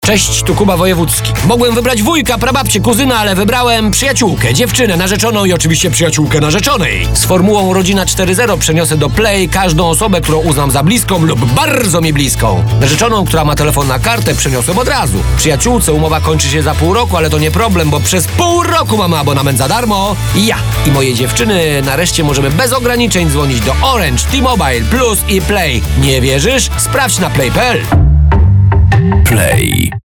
RADIO
30” radio FORMUŁA RODZINA_Kuba Wojewódzki